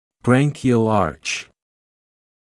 [‘bræŋkɪəl ɑːʧ][‘брэнкиэл аːч]жаберная дуга